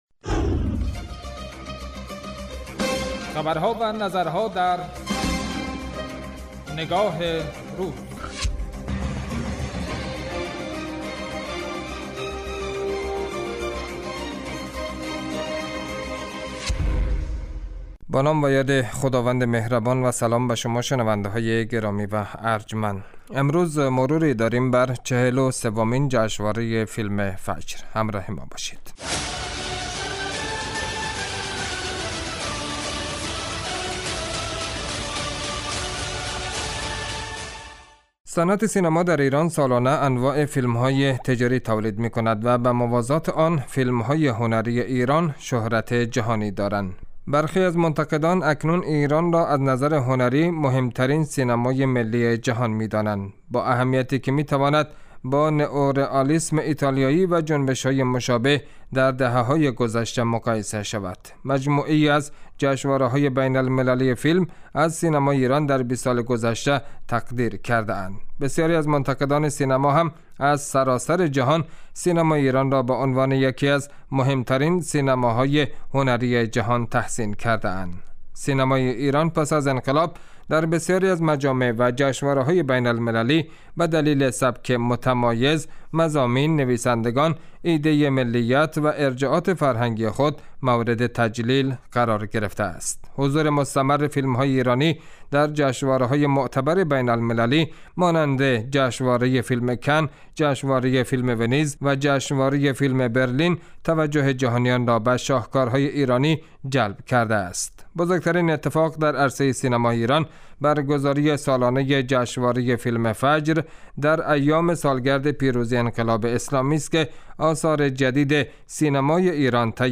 اطلاع رسانی و تحلیل و تبیین رویدادها و مناسبتهای مهم ، رویکرد اصلی برنامه نگاه روز است .